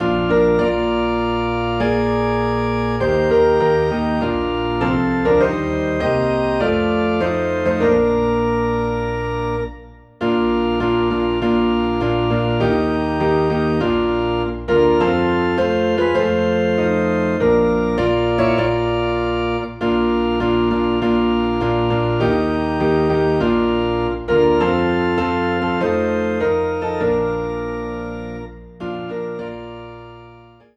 PIANO AND ORGAN DUET SERIES